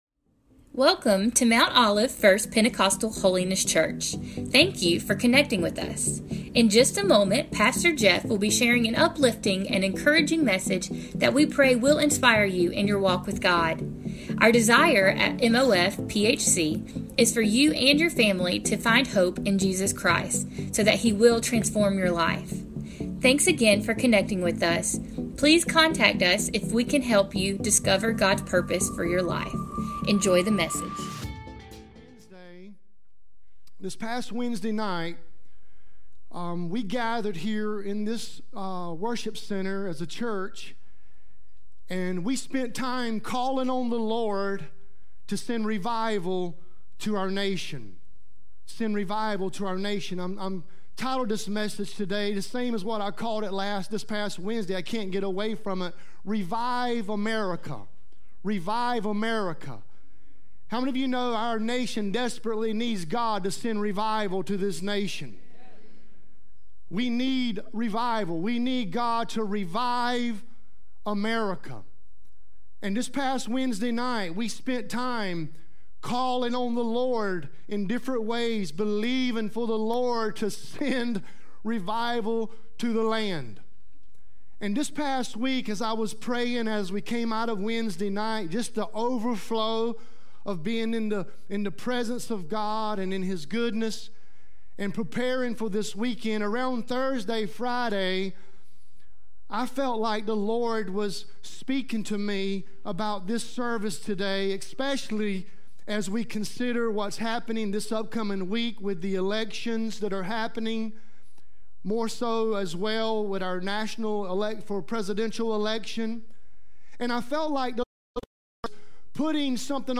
Mount Olive First PH Sermons